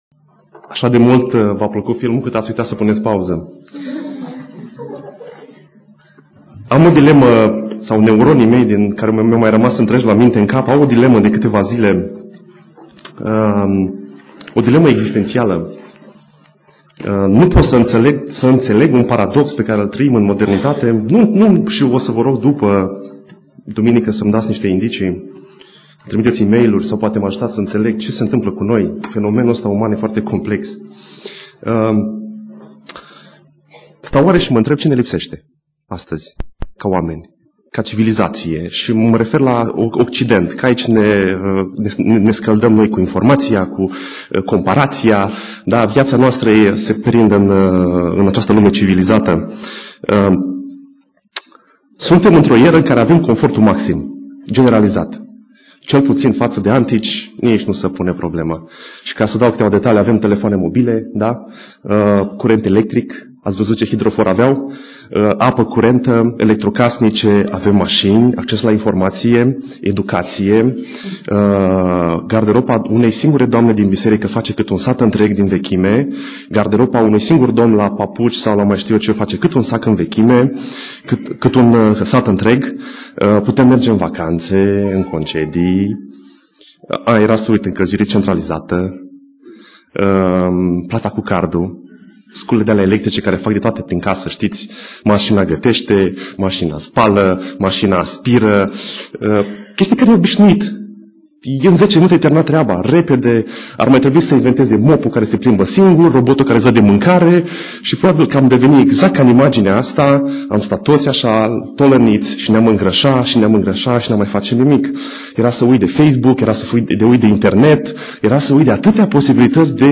Predica Exegeza - Nemultumirile